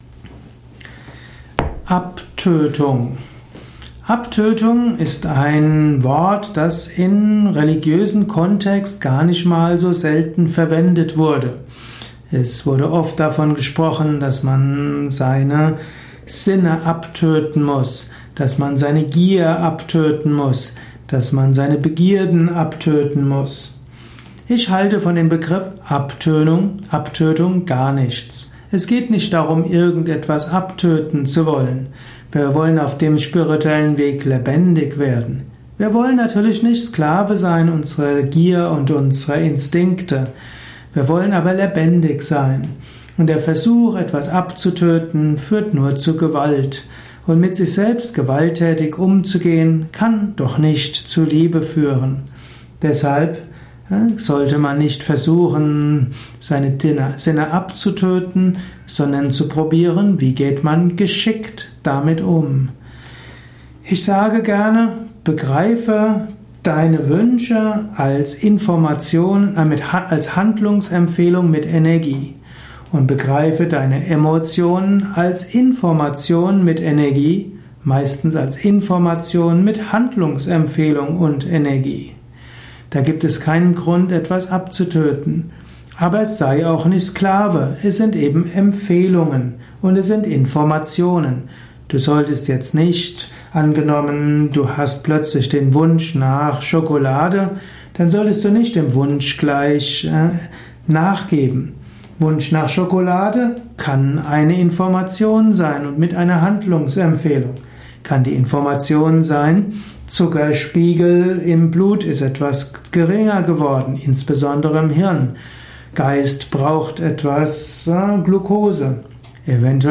Informationen und Anregungen zum Wort bzw. Ausdruck Abtötung in diesem kurzen Vortrag.